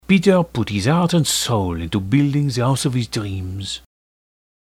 A French Accent
The accent I offer here is based on the accents heard among natives of France.
french.mp3